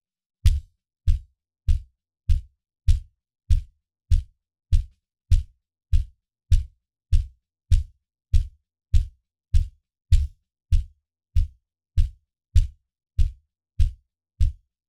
I’ve got a woeful sounding acoustic bass drum from over 40 minutes of a live recording. It sounds bad because of poor microphone recording, poorly tuned drums and a bassdrum beater bounce/rattle against the head.